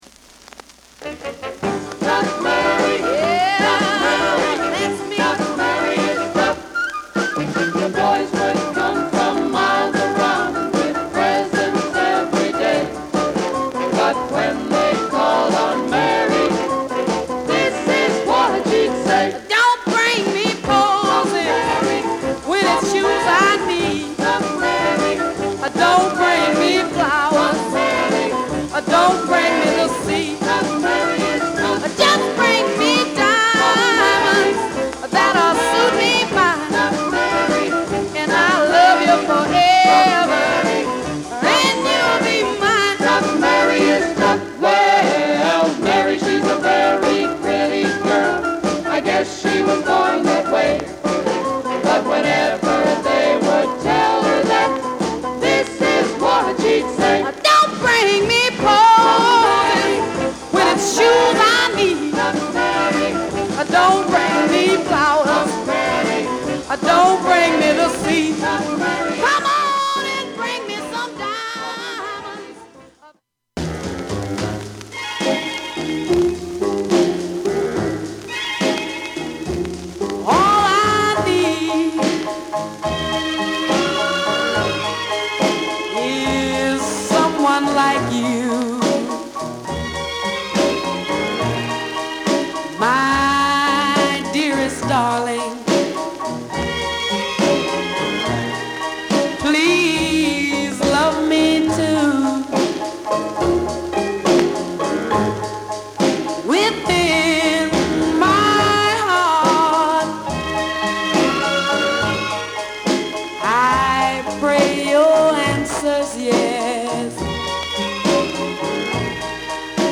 FUNKY R'N'R! & MELLOW SOUL BALLAD!!